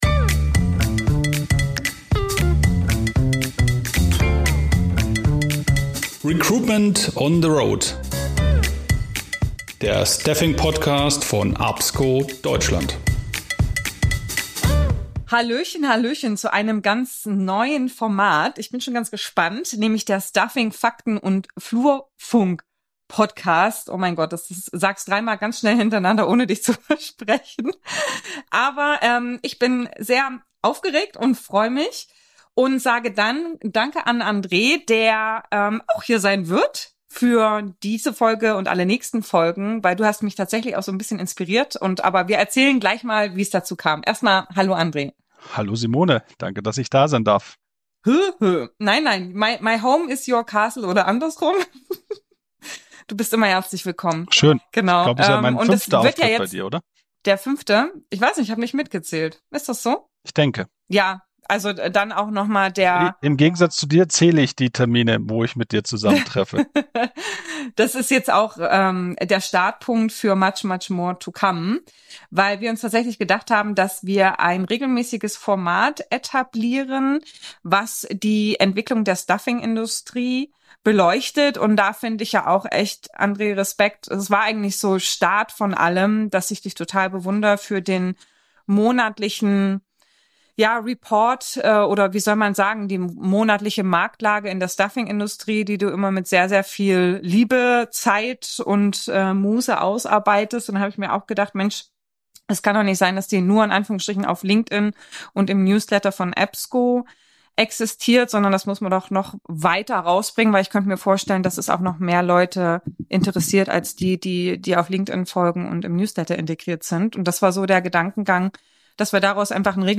Gemeinsam sprechen wir offen und direkt über alles, was die Staffingbranche bewegt.